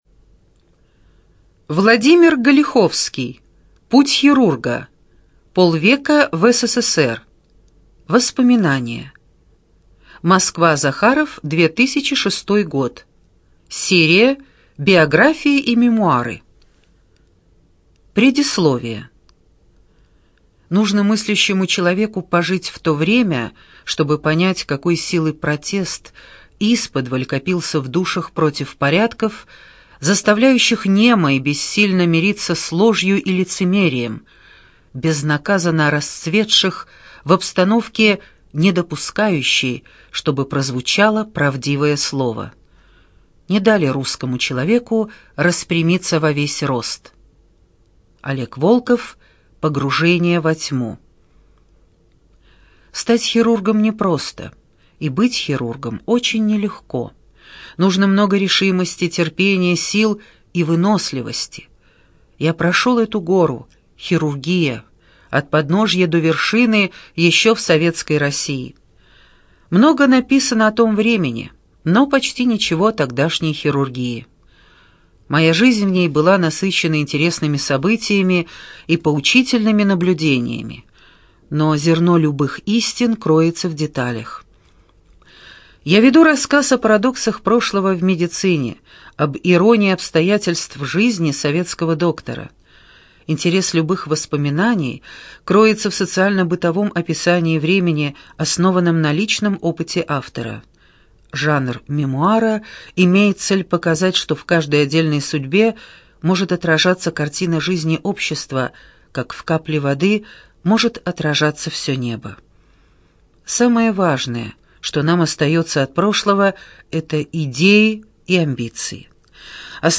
ЖанрБиографии и мемуары
Студия звукозаписиЛогосвос